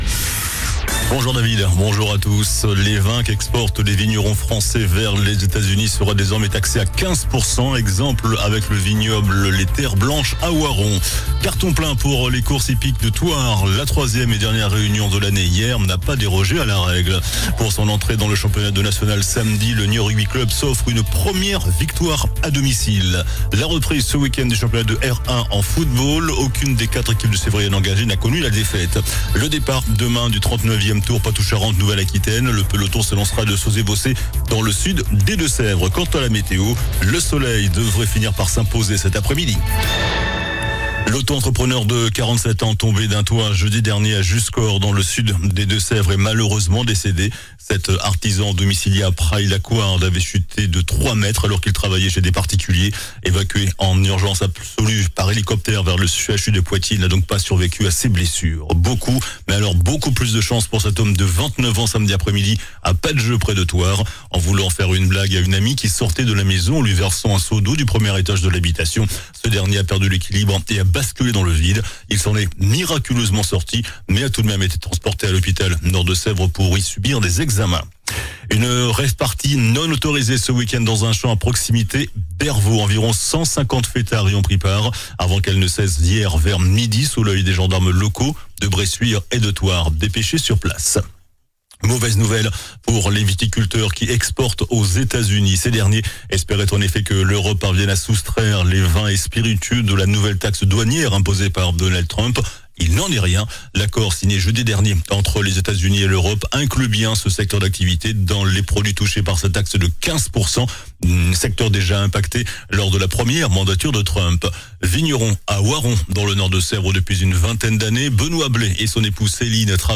JOURNAL DU LUNDI 25 AOÛT ( MIDI )